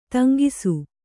♪ taŋgi